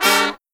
FALL HIT11-L.wav